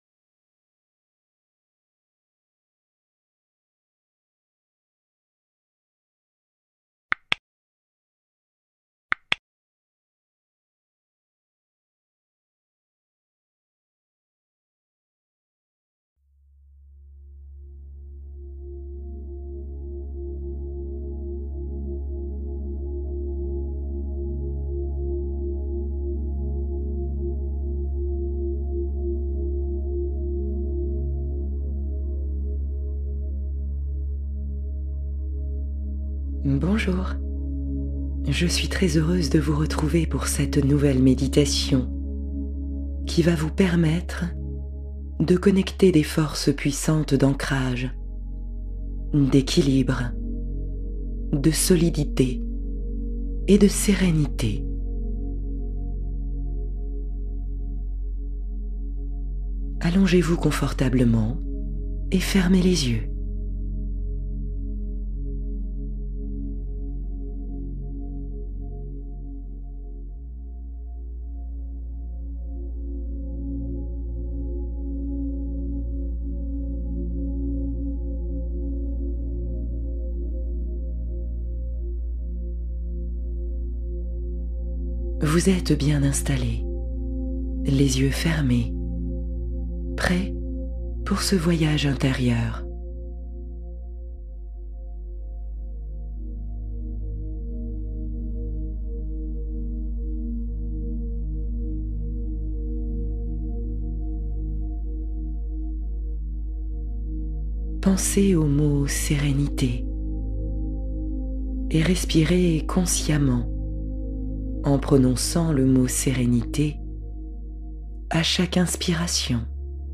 Apaiser stress et anxiété : guidance profonde de relâchement